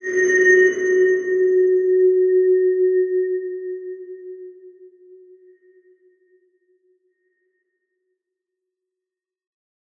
X_BasicBells-F#2-mf.wav